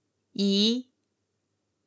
As you can see, the second tone (right) has a clear dip in it, even though you can probably disregard the high start there as pitch data is usually not reliable at the very end and very beginning of a sound liket his.
yi2.mp3